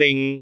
speech
cantonese
syllable
pronunciation
bing3.wav